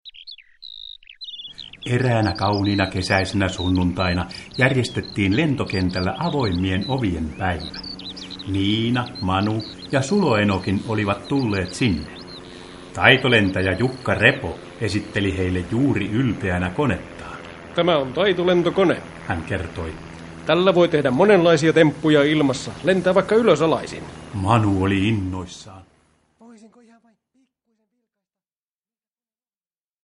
Miina ja Manu taitolentäjinä – Ljudbok – Laddas ner